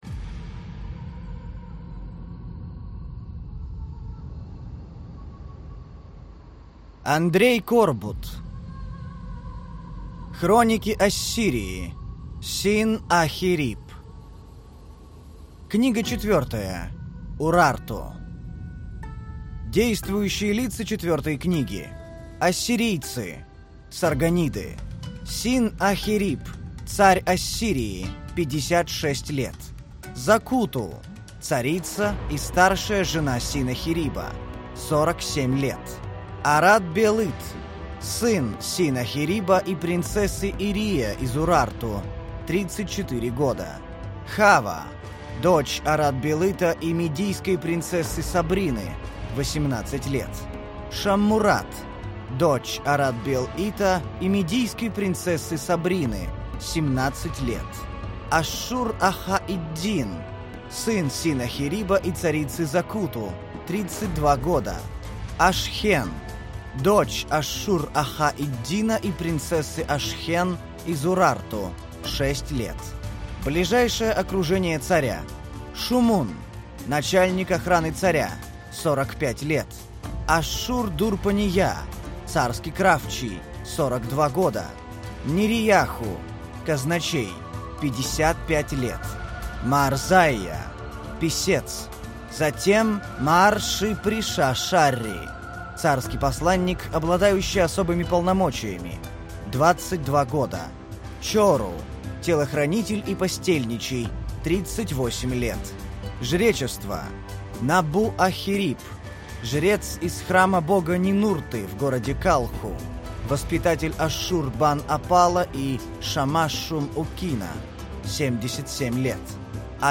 Аудиокнига Книга 4. Урарту | Библиотека аудиокниг